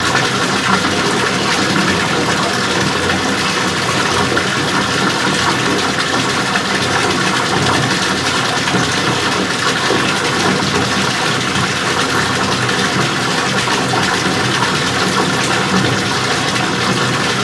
Bath Tub Filling Up With Water, Very Roomy